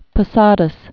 (pə-sädəs, pō-sädäs)